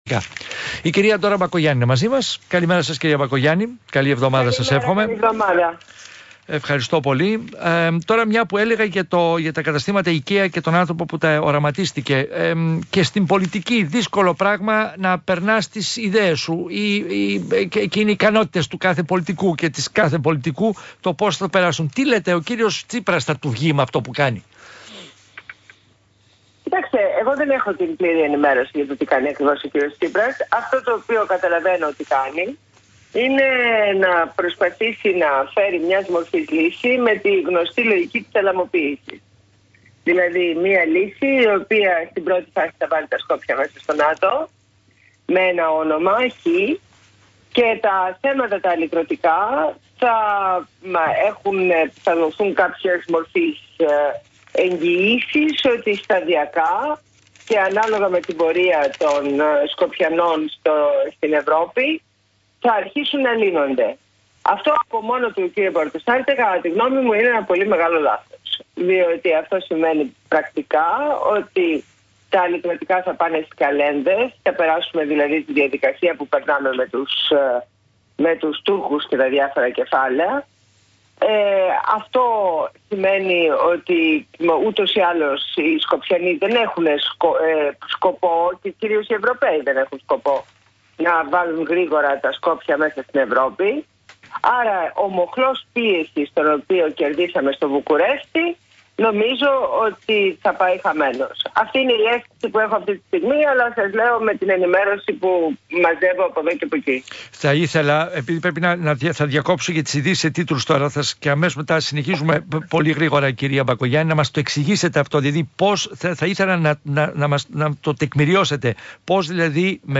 Συνέντευξη στο ραδιόφωνο του ΣΚΑΪ